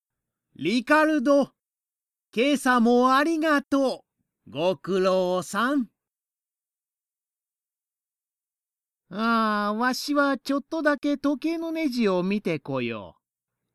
メインキャラクター・サブキャラクターの、イラスト＆簡単な紹介＆サンプルボイスです。